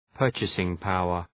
purchasing-power.mp3